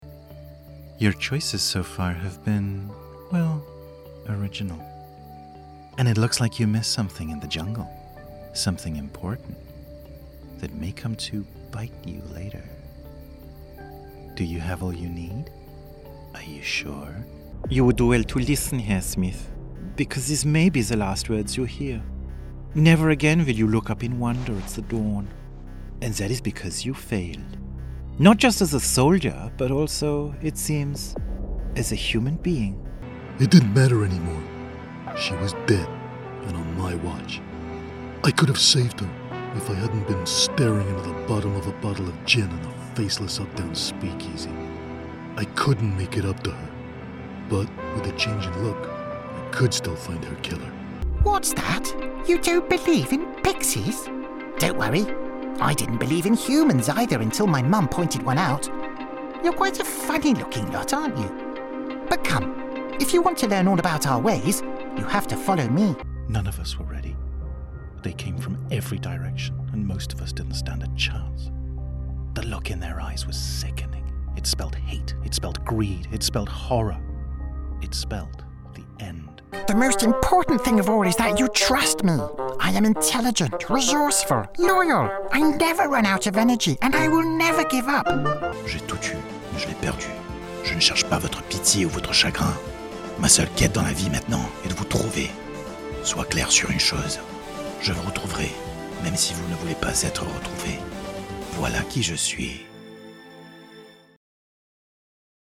Inglés (Británico)
Travieso, Seguro, Amable, Cálida, Empresarial